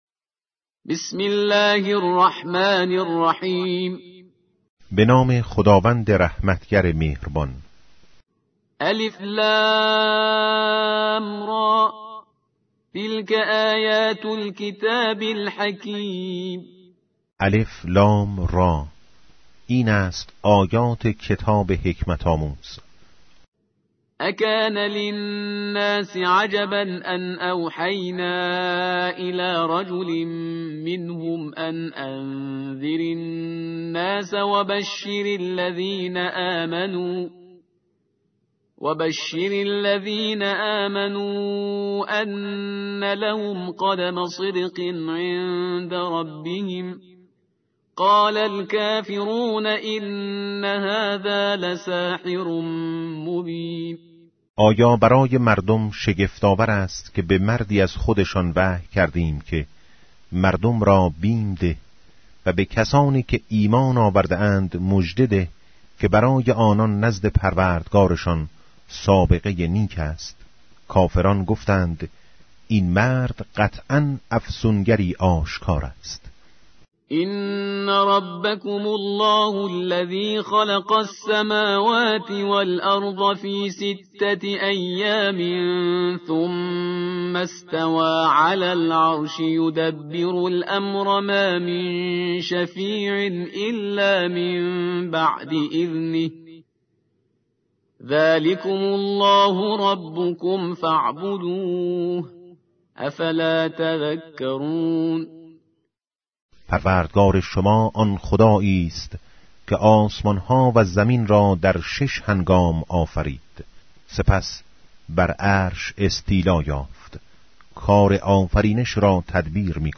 ترجمه و ترتیل قرآن کریم